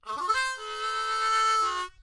口琴练习剪辑 " 口琴快速弯曲静音04
描述：我录制自己演奏口琴裂缝。
标签： 口琴 钥匙 G 裂谷
声道立体声